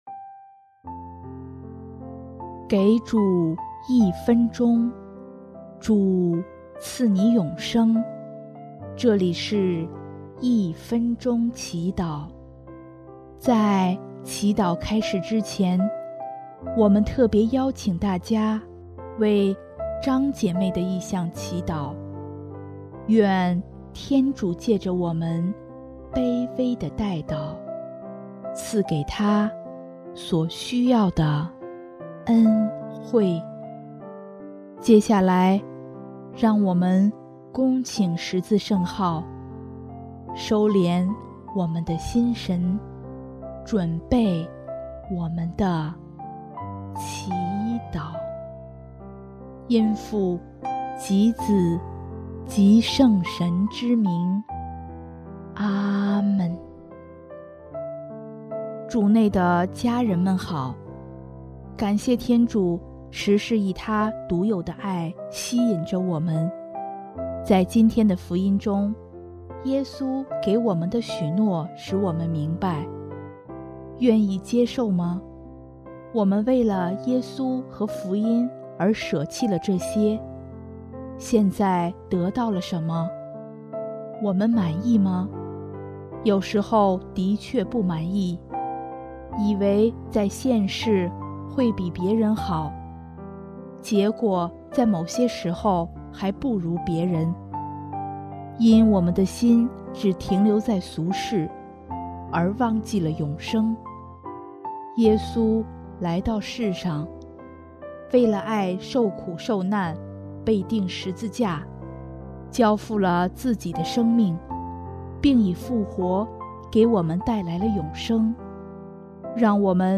【一分钟祈祷】|5月28日 祂独有的爱吸引着我们